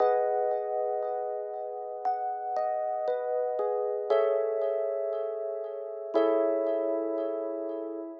04 ElPiano PT4.wav